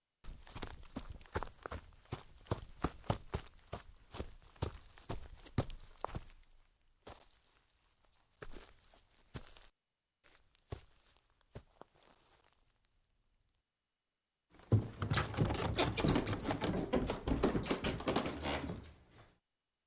CORRER
CORRER - Tono movil - EFECTOS DE SONIDO
Tonos gratis para tu telefono – NUEVOS EFECTOS DE SONIDO DE AMBIENTE de CORRER
Correr.mp3